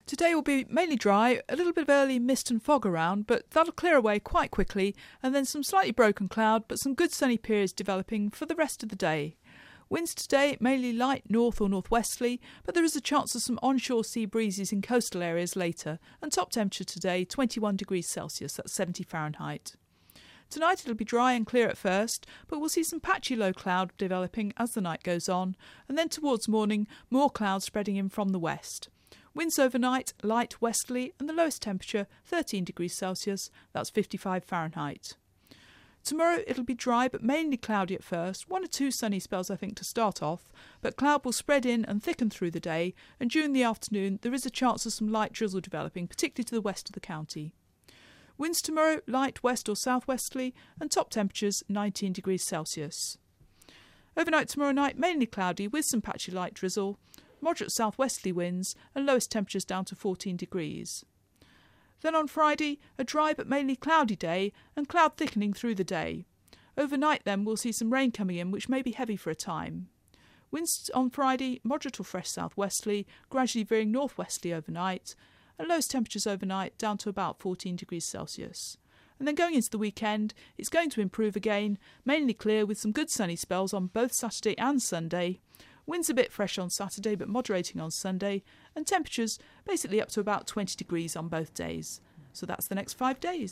5 Day Weather Forecast for Cornwall & the Isles of Scilly from 0815 on 28 August